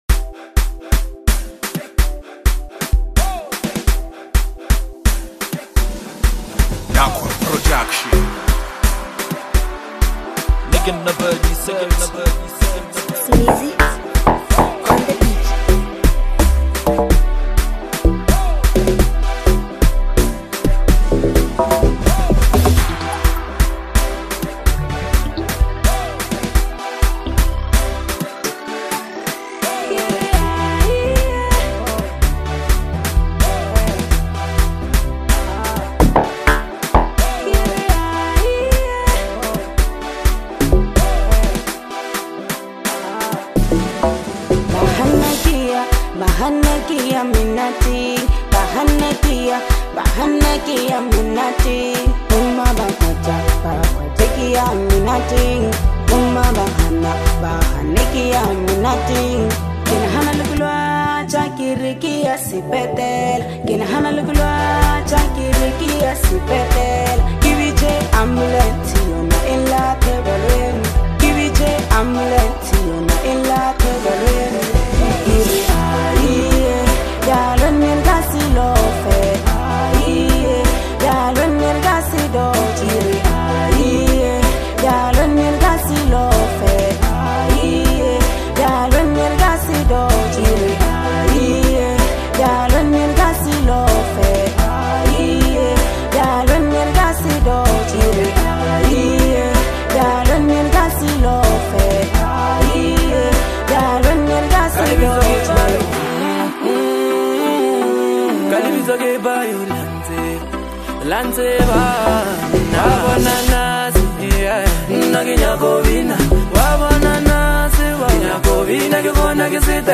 Home » Amapiano » DJ Mix » Hip Hop